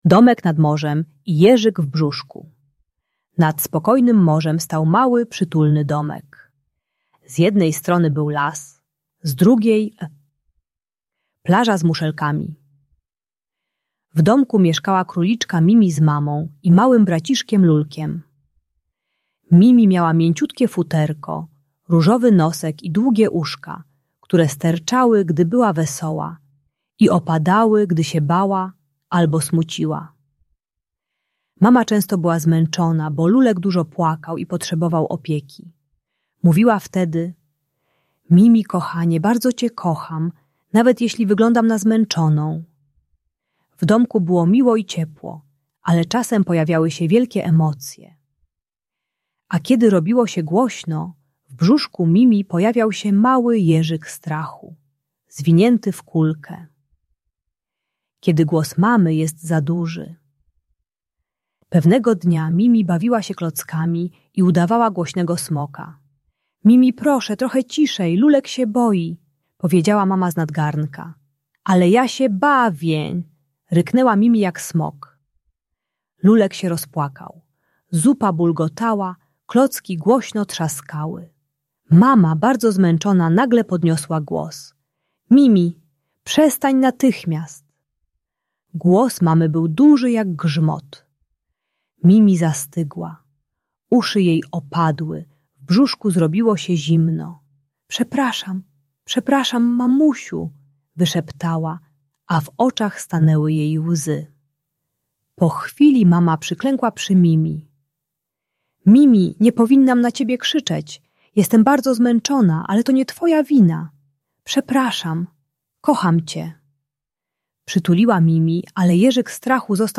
Uczy techniki "oddech misia" - głębokie oddychanie z brzuszka, które pomaga dziecku i rodzicowi uspokoić emocje zamiast krzyczeć. Audiobajka o radzeniu sobie ze strachem przed złością dorosłych.